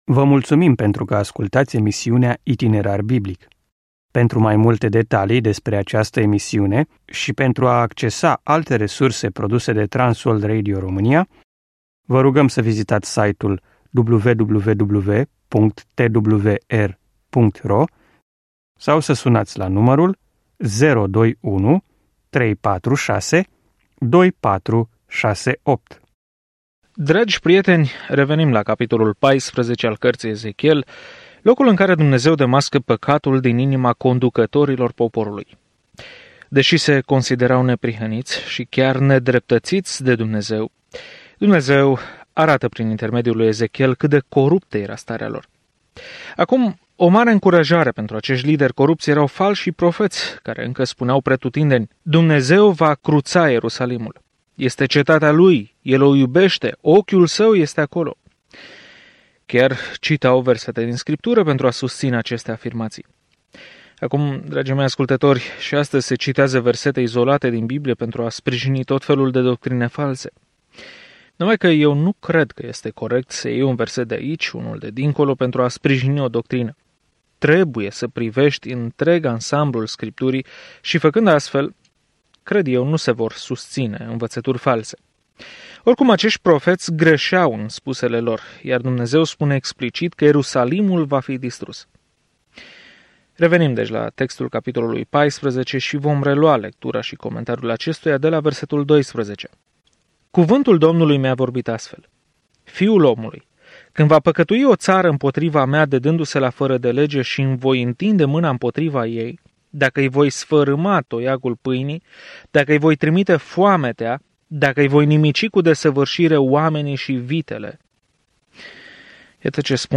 Scriptura Ezechiel 14:12-23 Ezechiel 15 Ezechiel 16 Ezechiel 17 Ziua 9 Începe acest plan Ziua 11 Despre acest plan Oamenii nu au vrut să asculte cuvintele de avertizare ale lui Ezechiel de a se întoarce la Dumnezeu, așa că, în schimb, el a jucat pildele apocaliptice și le-a străpuns inimile oamenilor. Călătoriți zilnic prin Ezechiel în timp ce ascultați studiul audio și citiți versete selectate din Cuvântul lui Dumnezeu.